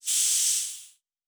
Air Hiss 1_04.wav